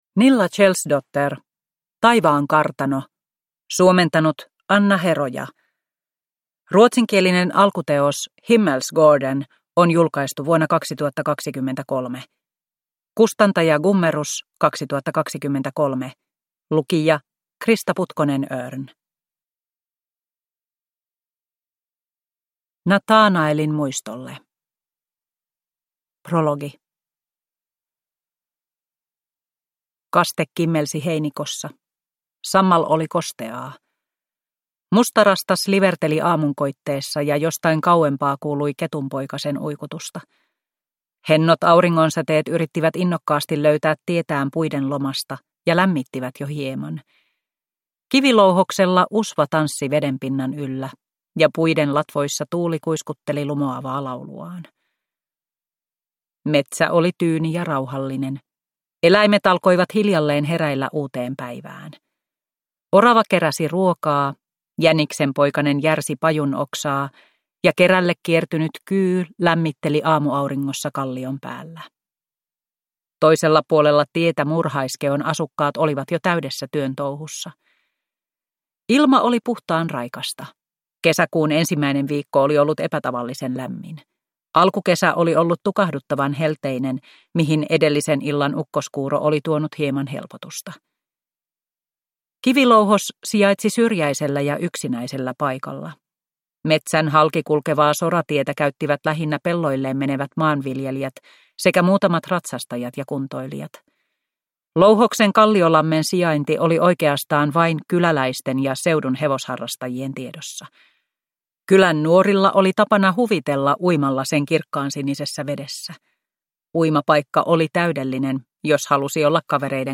Taivaankartano – Ljudbok – Laddas ner